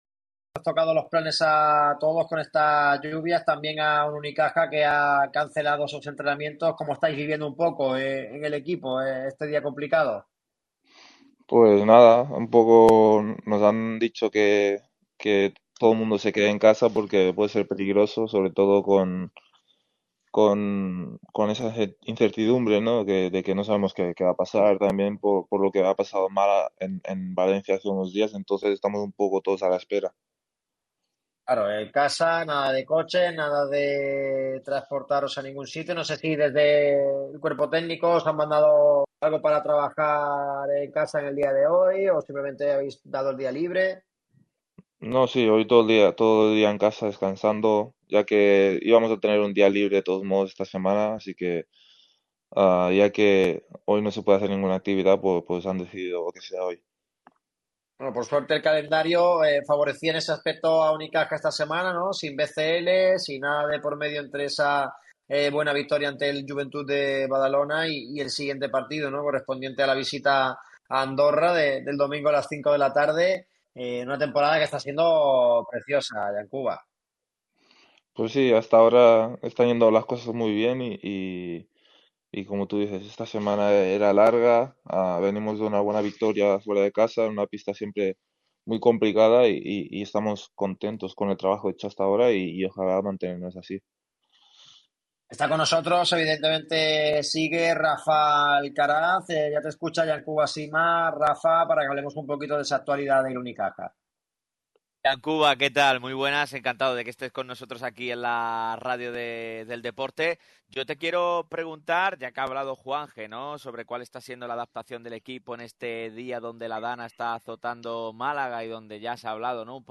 En este miércoles donde Málaga está paralizada con alerta roja por culpa de la DANA, el pívot gerundense ha pasado por los micrófonos de la radio del deporte.